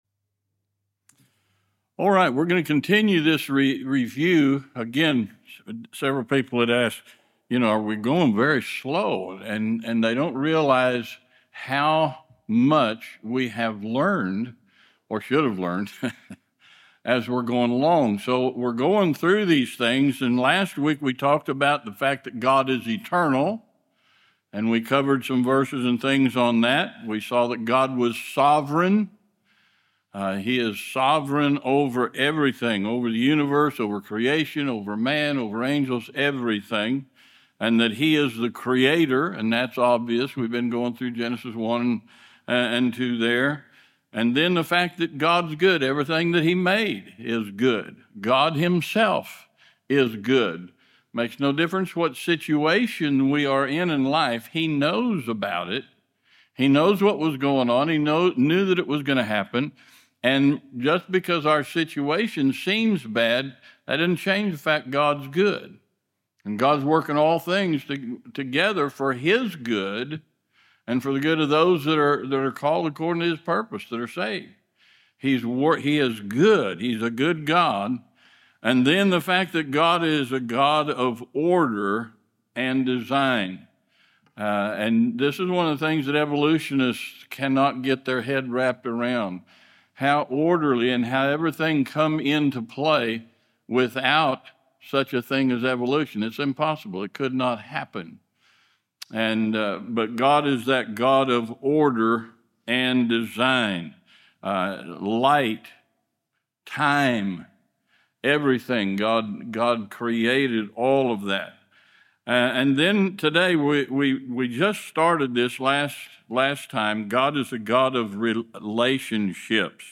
This message highlights God’s sovereignty, goodness, order, moral authority, power, mercy, holiness, justice, and faithfulness, while also emphasizing that God gave man a real choice. The sermon challenges Calvinistic ideas and calls you back to the clear teaching of Genesis: God is holy, man is accountable, and the Bible’s foundation begins right here.